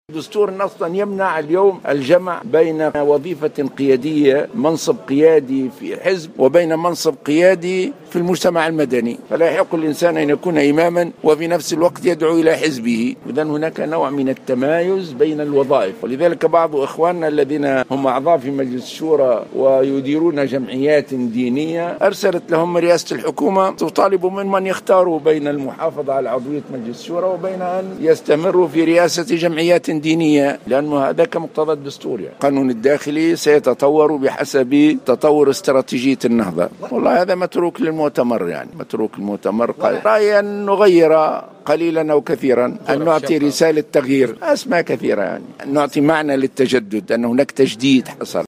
قال رئيس حركة النهضة راشد الغنوشي على هامش المؤتمر الجهوي للحركة اليوم الأحد بسوسة أن الدستور يمنع الجمع بين وظيفة قيادية في حزب سياسي ووظيفة قيادية في المجتمع المدني.